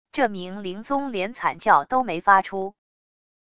这名灵宗连惨叫都没发出wav音频生成系统WAV Audio Player